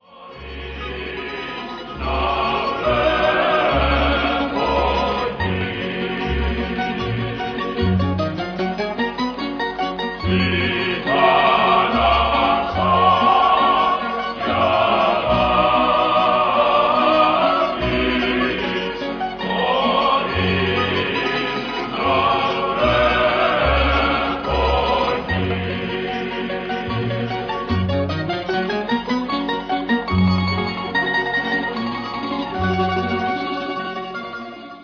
The serenade in Ionian Islands
It is characterized by western type polyphony, consisting of two or more independent melodic voices as opposed to music with just one voice.
The songs were usually accompanied by guitars and mandolins, though they were often sang without instrument accompaniment.